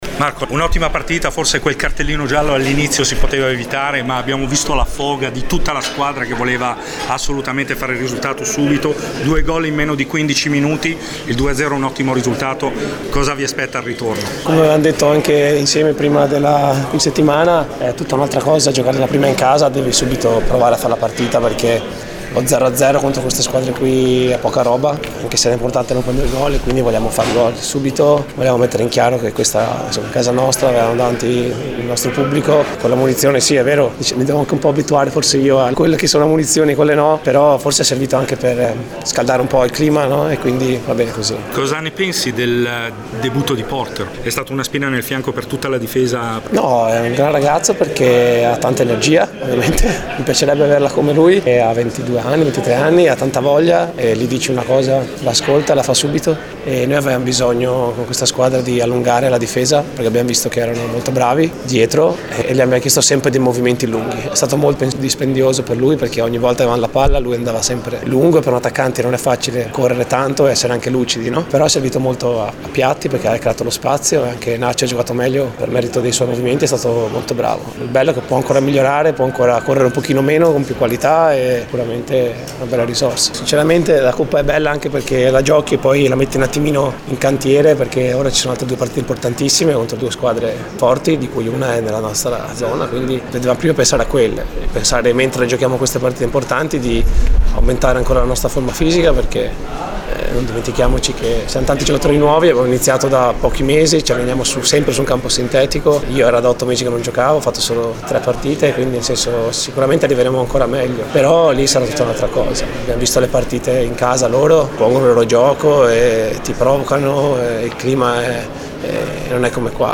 Le interviste: